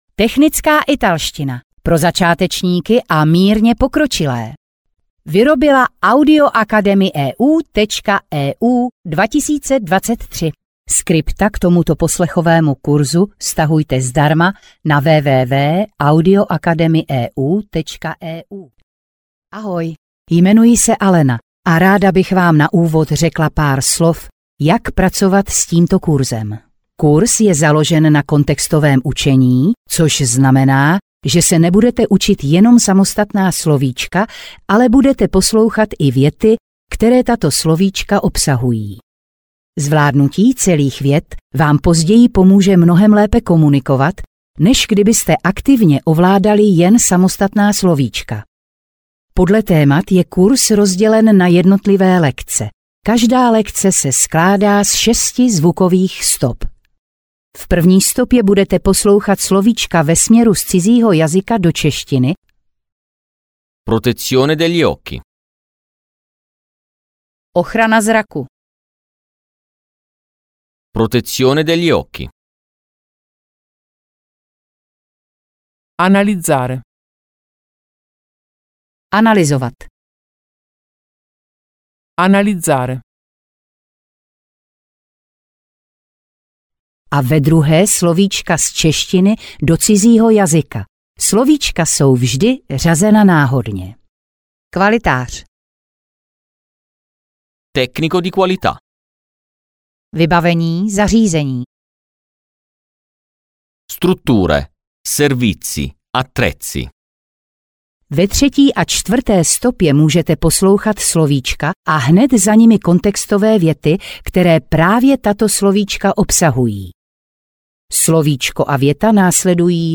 Audiokniha